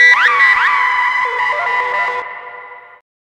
OLDRAVE 4 -R.wav